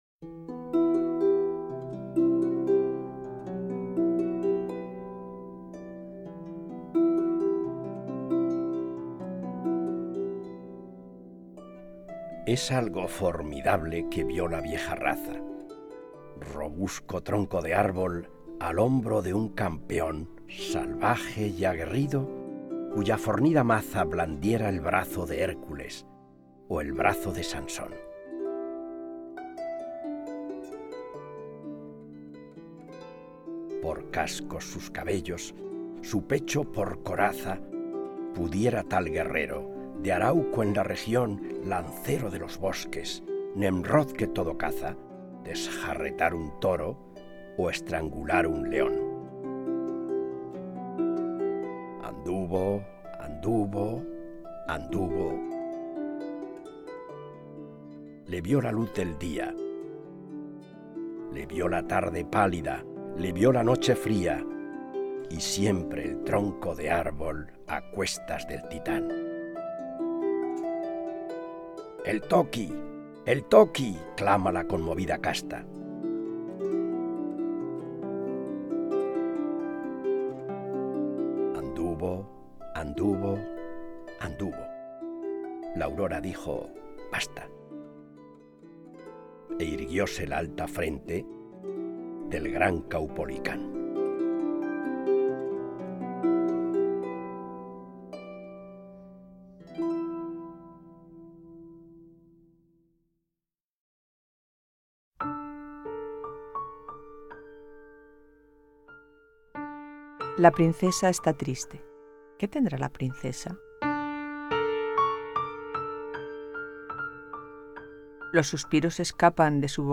recitar